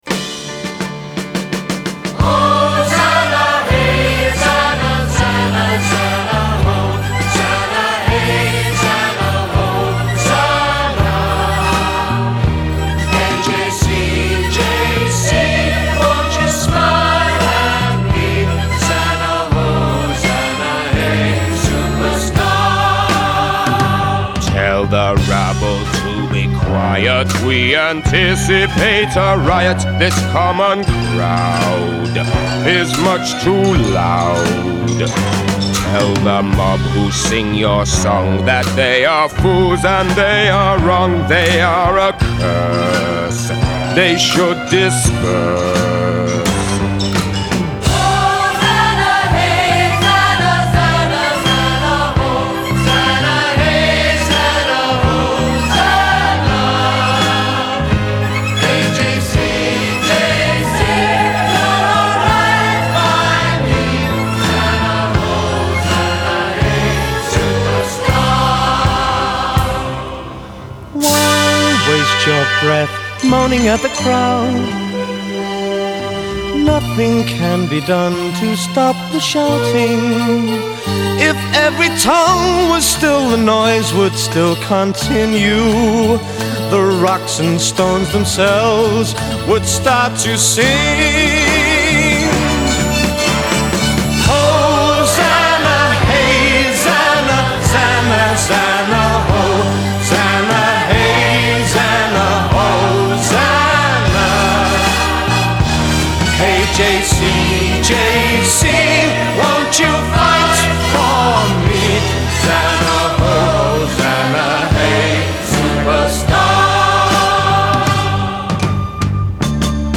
Genre : Musical Theatre